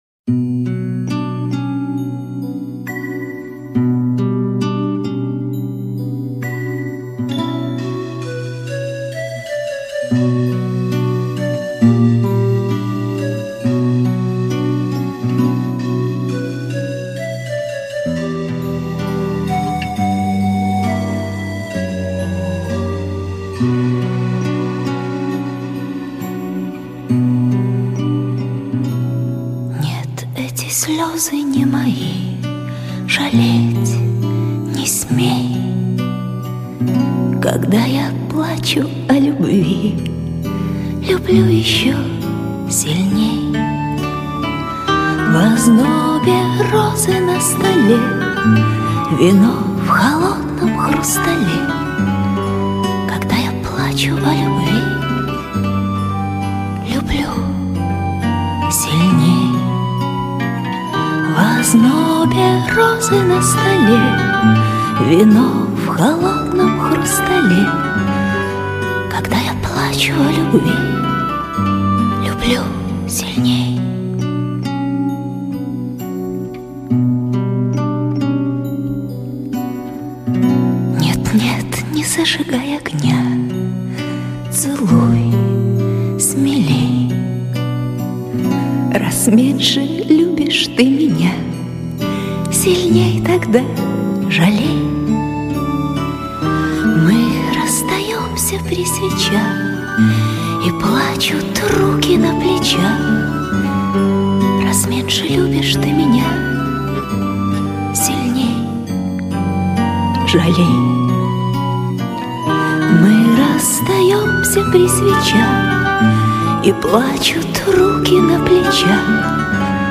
Романсы